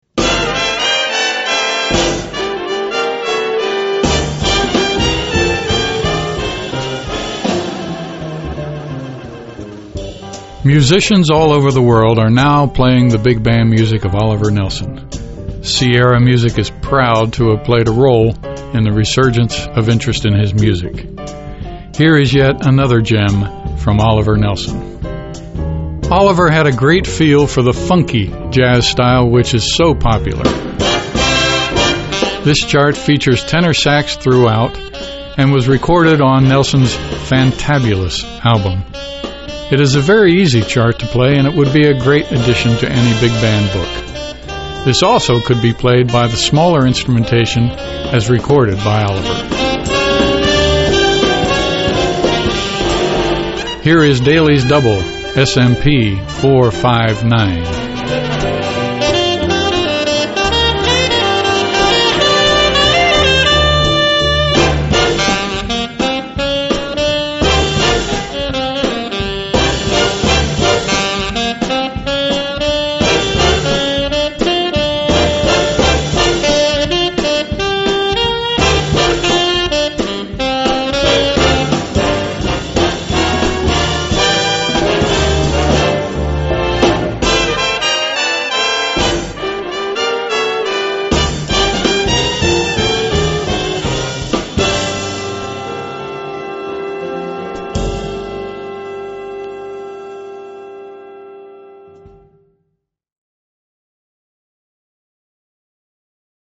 "funky" jazz style
This chart features tenor sax throughout